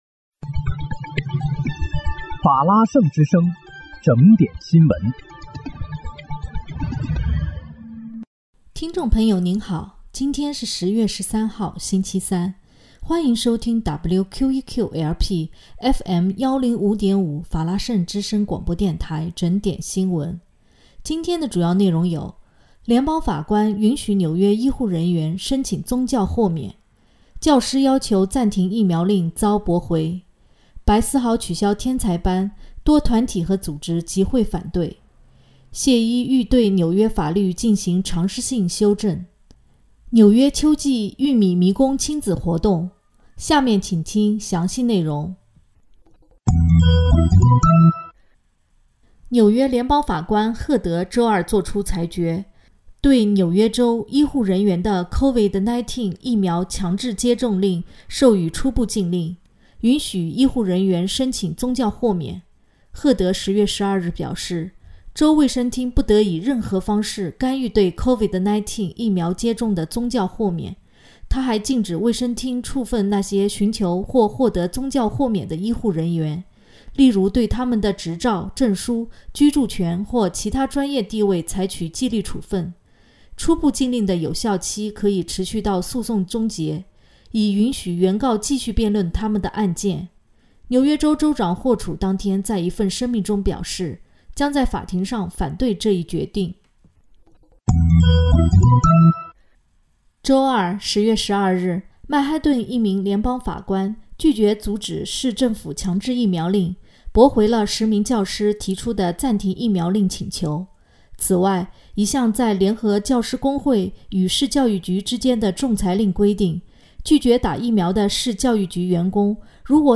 10月13日（星期三）纽约整点新闻